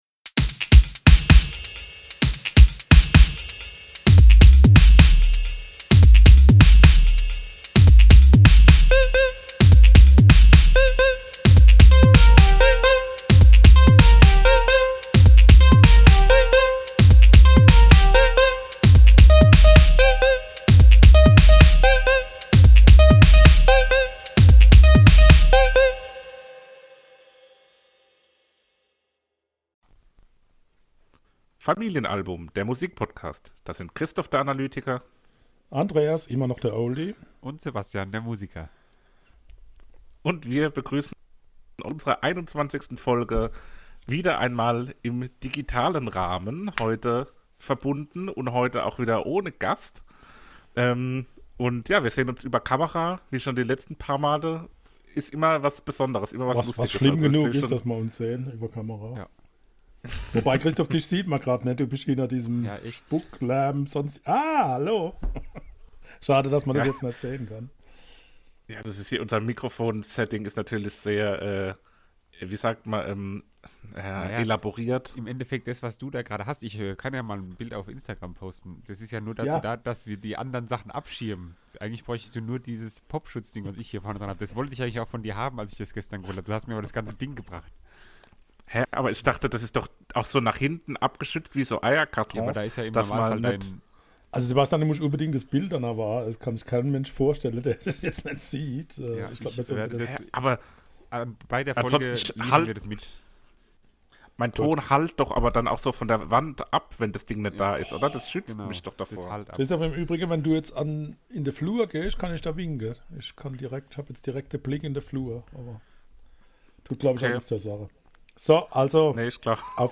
*Hoffentlich bald wieder mit „Vor-Ort-Aufnahme“ und dann auch wieder unserem beliebten Wein der Folge, der zur Zeit der digitalen Aufnahme-Form zum Opfer fällt.